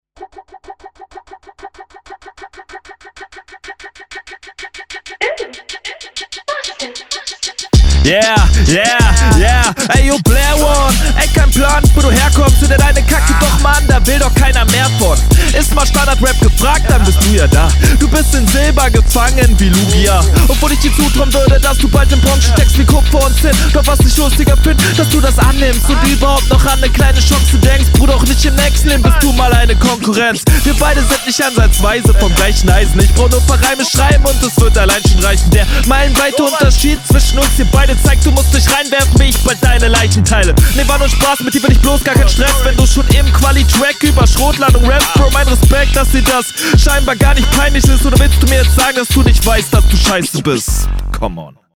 Sehr Cooler Flow auch der Einstieg hat mir sehr gefallen.
Flow ist cool, Mix ist strong, Punches hitten, Reime gehen klar. Coole Runde